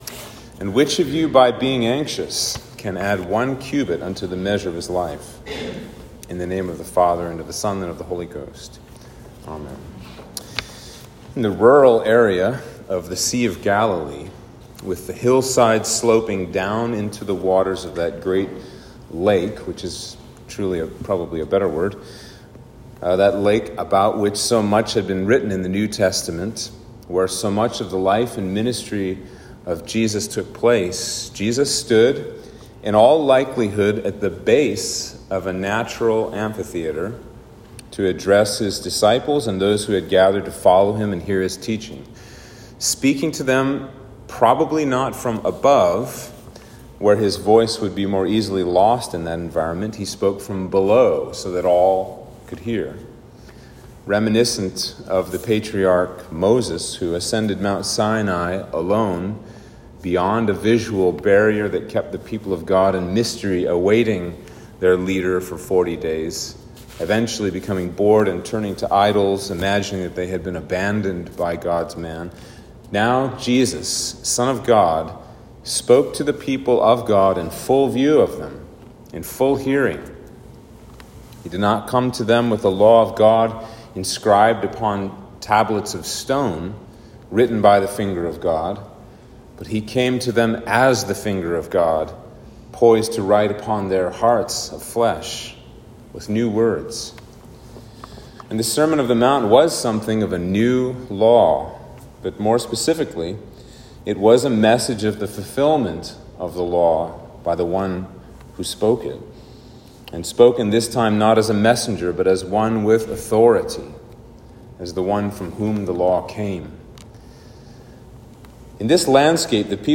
Sermon for Trinity 15